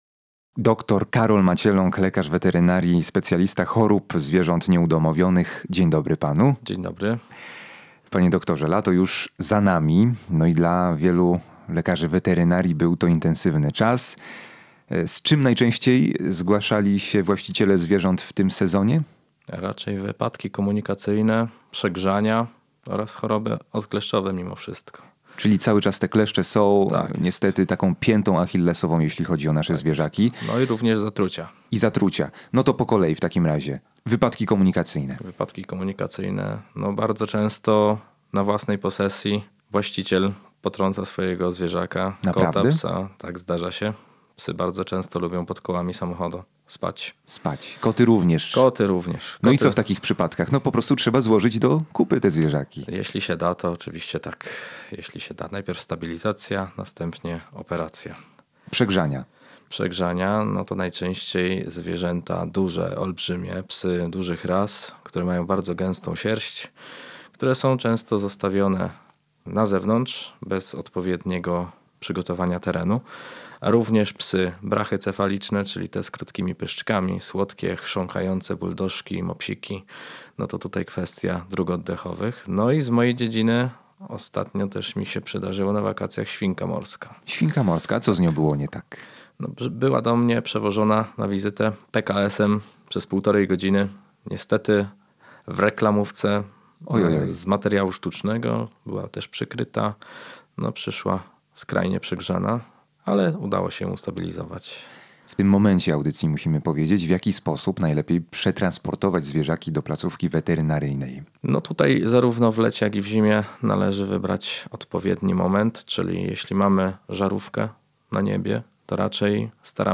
specjalista chorób zwierząt nieudomowionych, w audycji „Na cztery łapy” wskazał najczęstsze jesienno-zimowe zagrożenia dla pupili oraz podał praktyczne zasady profilaktyki – od transportu, przez ochronę przed kleszczami, po higienę jamy ustnej i opiekę w sezonie zimowym.